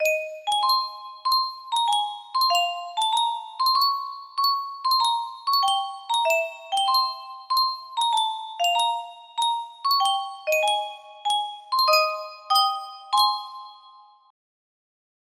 Yunsheng Music Box - Beethoven Symphony No. 5 2nd Movement 4013 music box melody
Full range 60